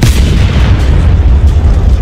mbtfire4.ogg